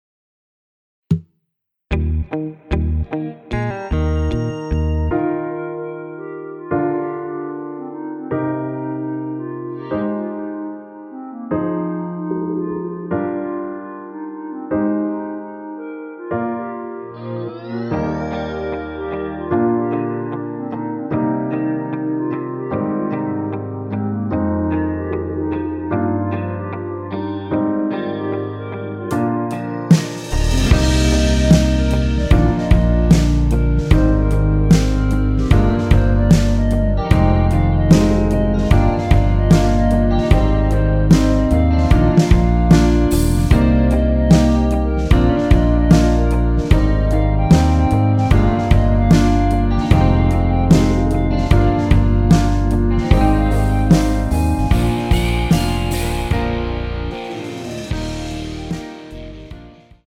원키에서(-3)내린 멜로디 포함된 MR입니다.(미리듣기 확인)
Eb
앞부분30초, 뒷부분30초씩 편집해서 올려 드리고 있습니다.
중간에 음이 끈어지고 다시 나오는 이유는